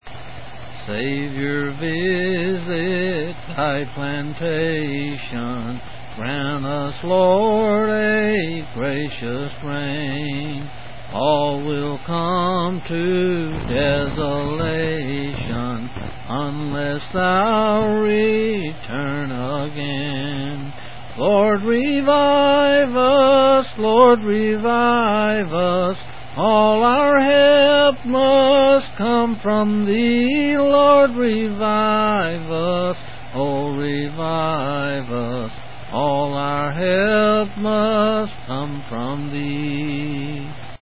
8's 7's